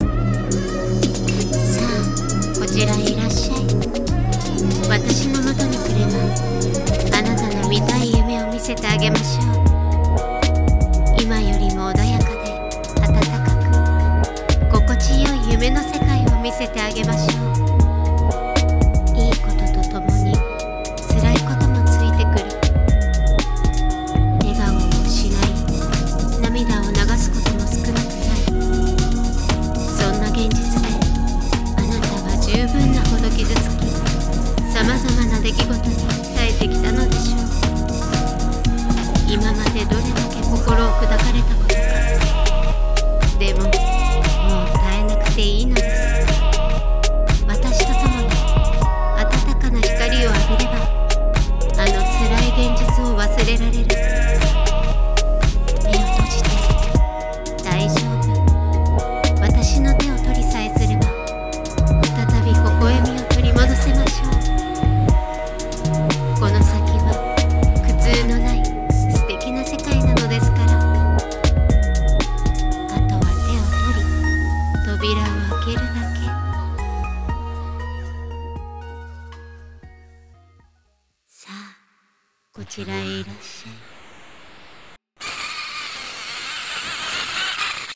【一人声劇】女神か、悪魔か 🐍